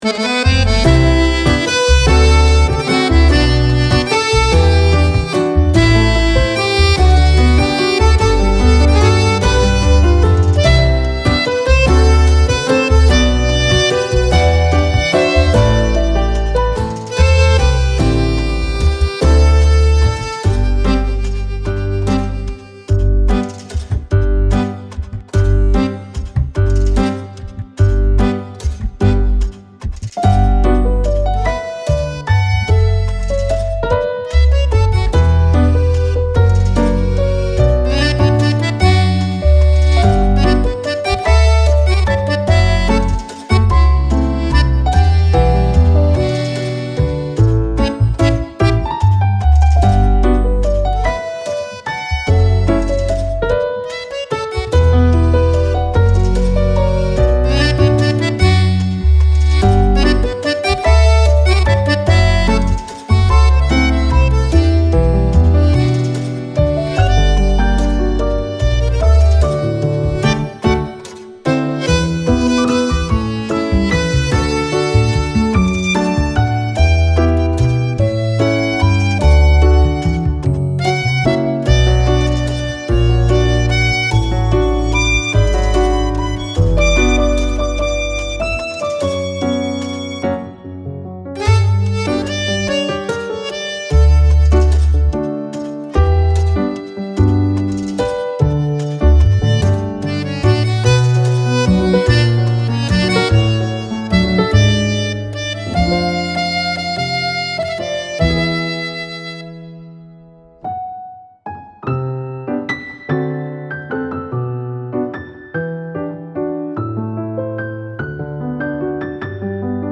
暖かくて、懐かしくてて、切ないBGM
アコースティック, ポップ 6:54